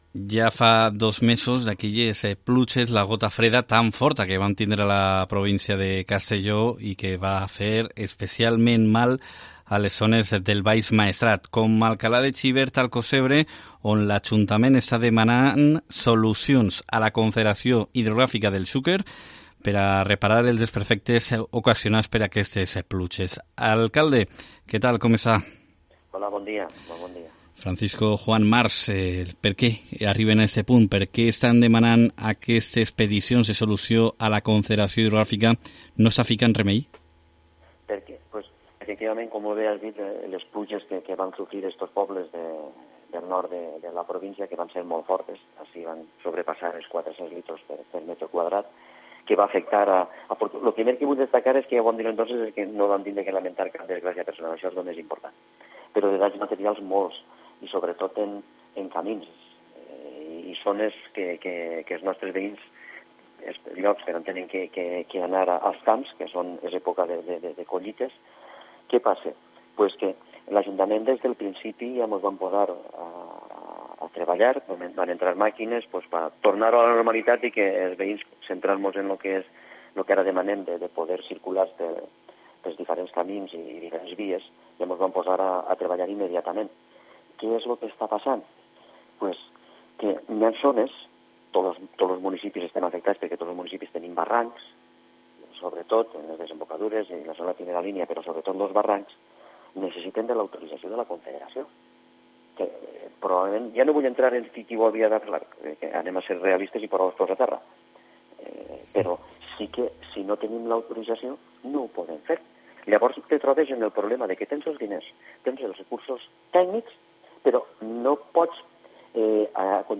Entrevista a Francisco Juan Mars, alcalde de Alcalà de Xivert